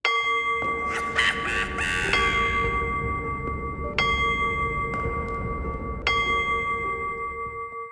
cuckoo-clock-04.wav